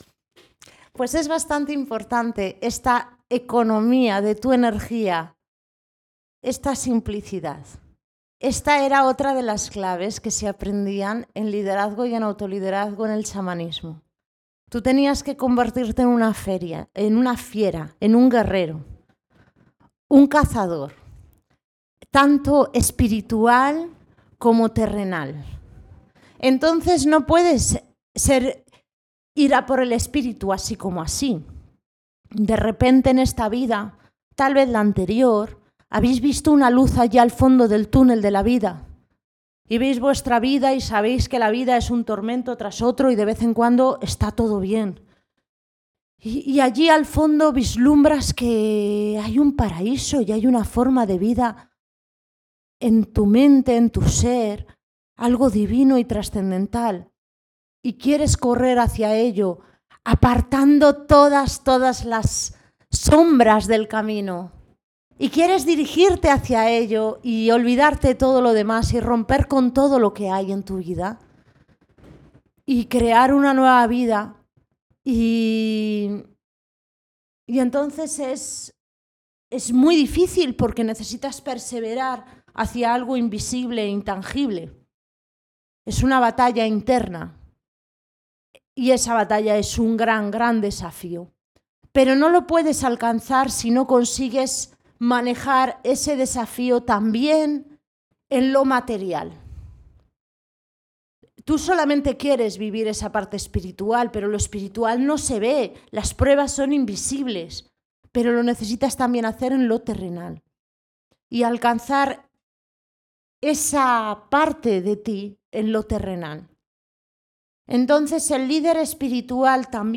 Formación, charla Iniciando un nuevo proyecto 2º parte